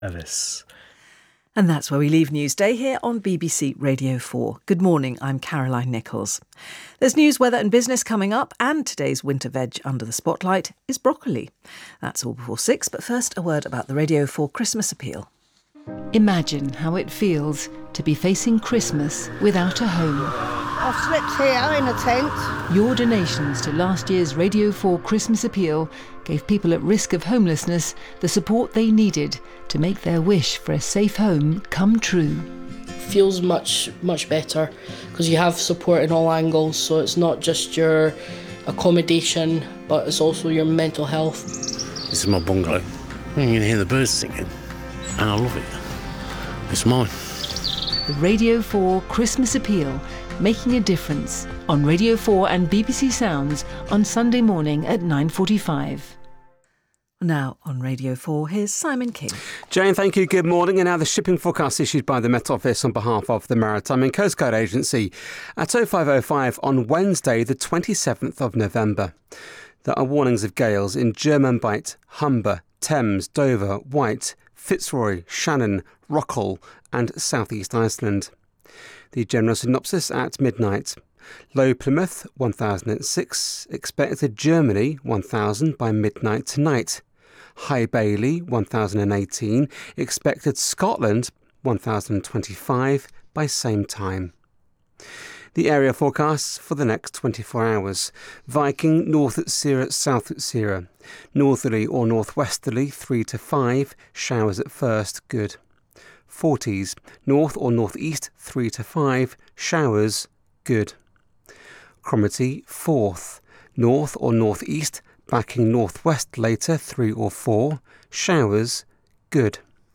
The latest weather reports and forecasts for UK shipping